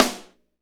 Index of /90_sSampleCDs/Northstar - Drumscapes Roland/KIT_R&B Kits/KIT_R&B Dry Kitx
SNR R B S07R.wav